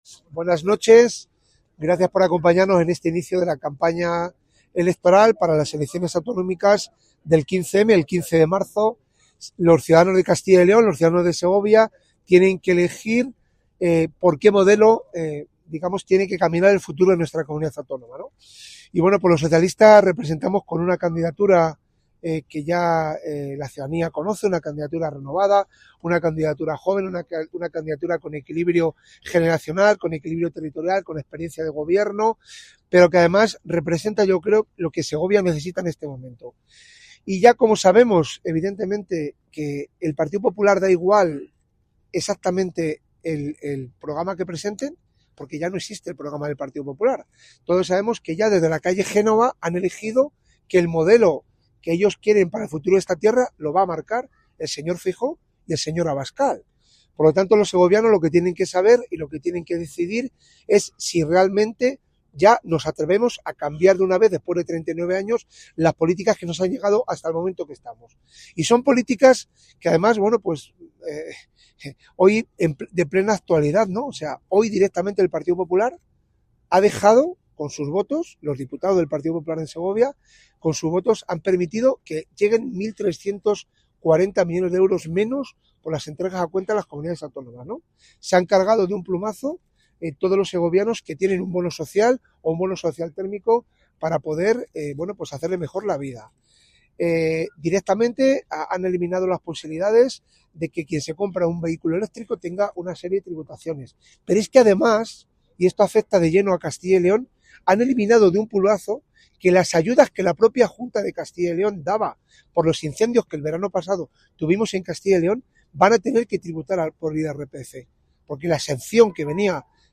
• El PSOE de Segovia ha celebrado su acto de inicio de campaña en el parque del reloj en Nueva Segovia para las elecciones autonómicas del 15 de marzo, reuniendo a la candidatura, militantes y simpatizantes en un encuentro marcado por la ilusión y la convicción de que la provincia necesita un cambio.